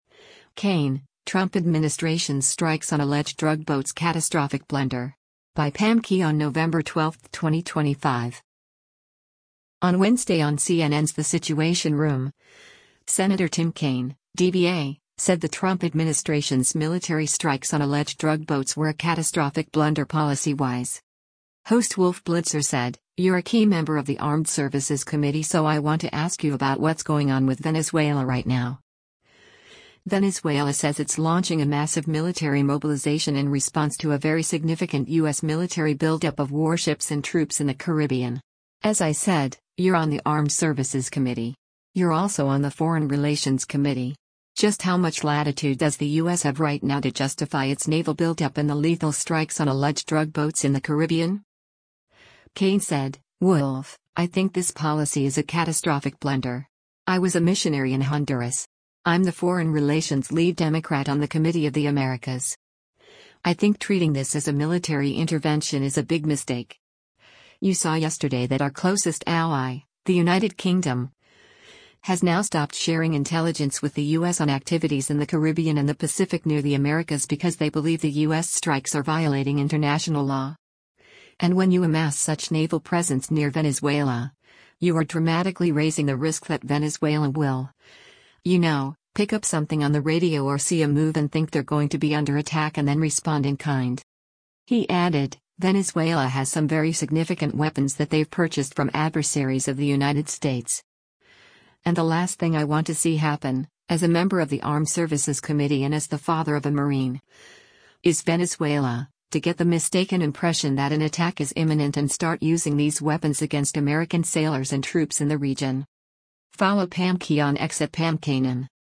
On Wednesday on CNN’s “The Situation Room,” Sen. Tim Kaine (D-VA) said the Trump administration’s military strikes on alleged drug boats were a “catastrophic blunder” policy-wise.